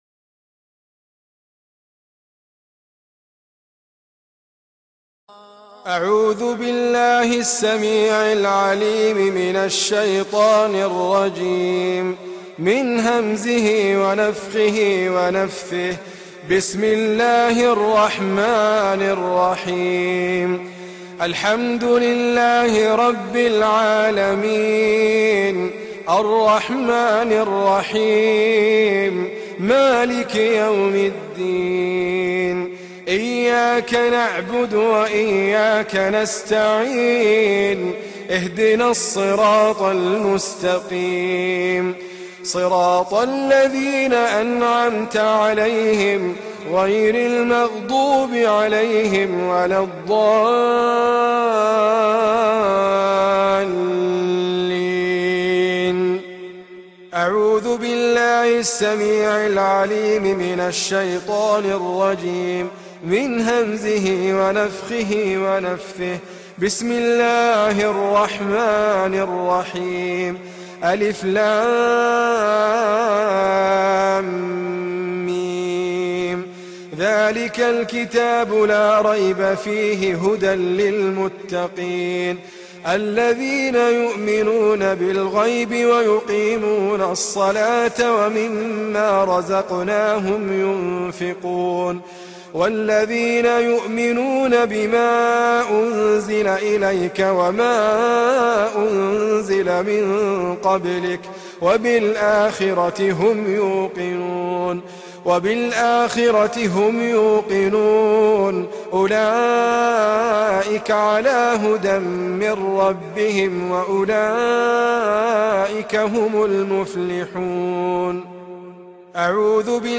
رقية شرعية بصوت القارى ادريس ابكر